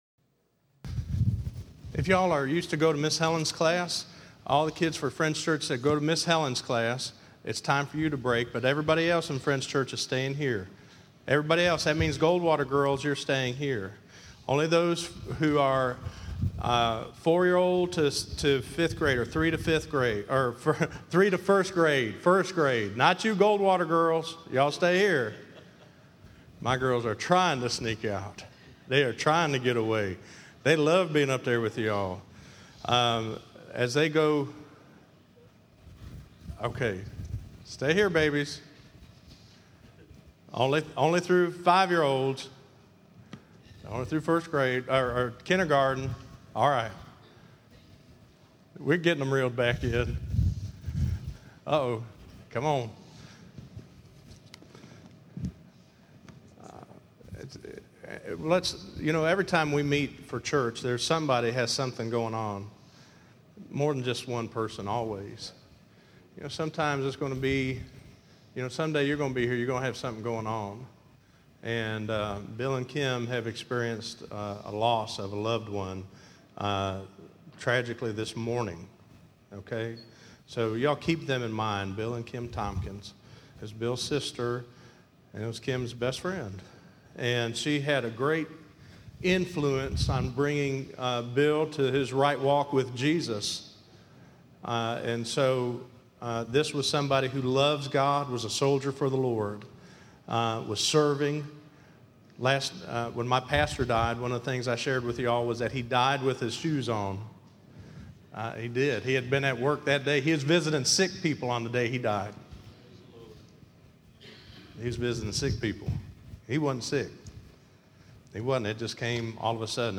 Listen to Finding Freedom In Jesus Christ - 05_31_15_sermon.mp3